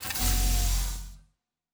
Door 3 Open.wav